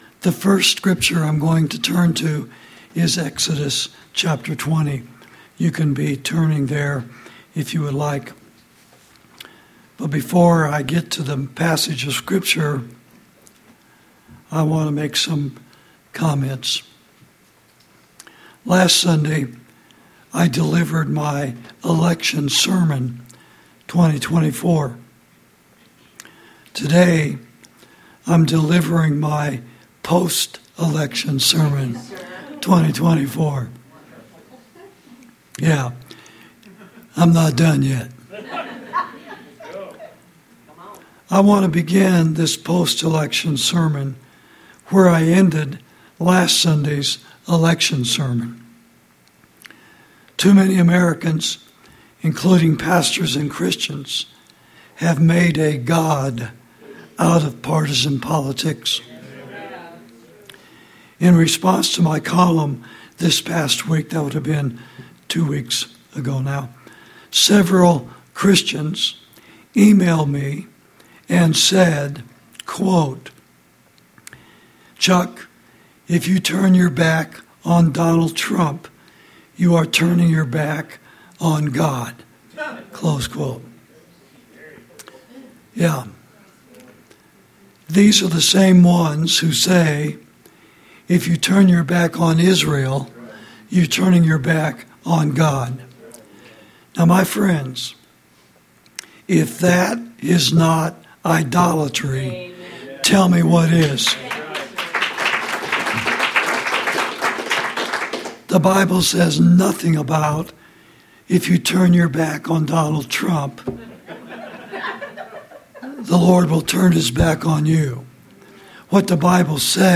Post-Election Sermon 2024